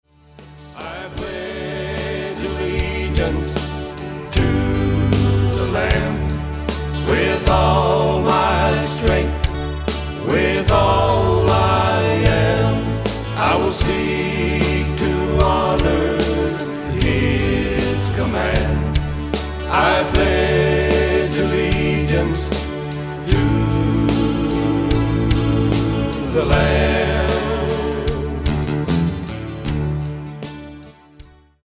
Autoharp
Drums, Lead and Harmony Vocals
Guitar
Bass
Keyboards
Rhythm guitar